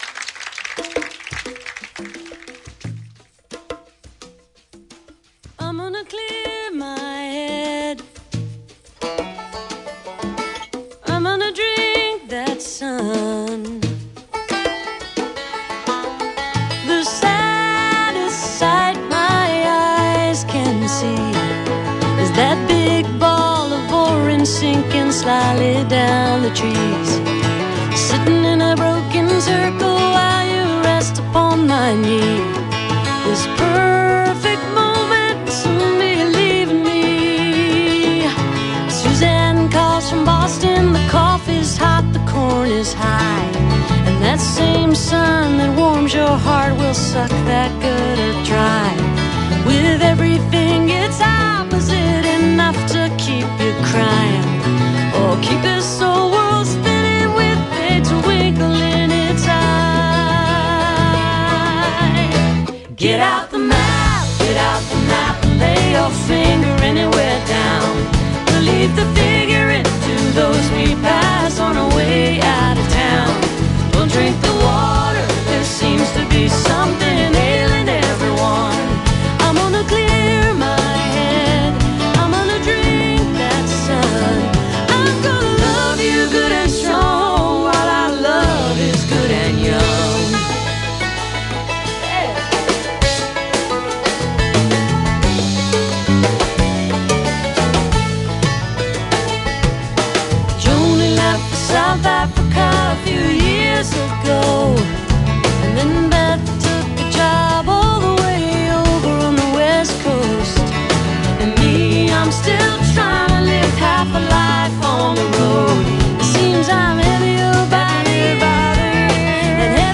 (album version)